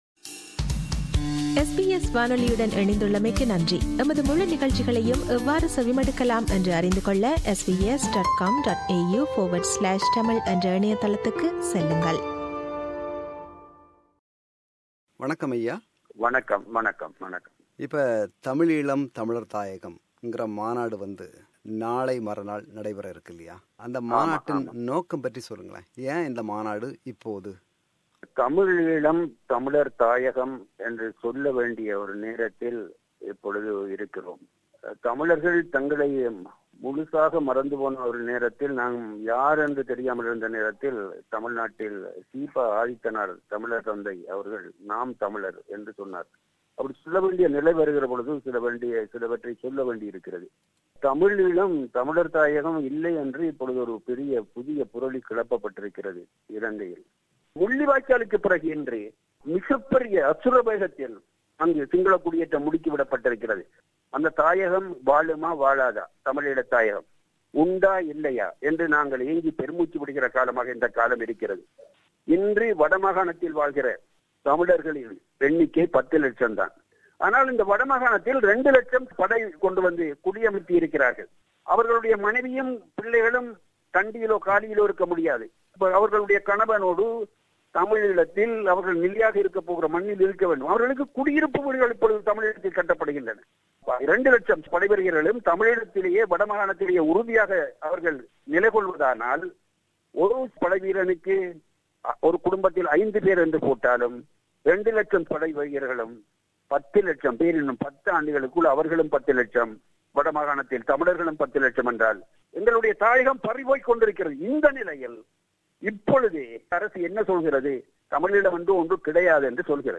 Interview with Kasi Anandan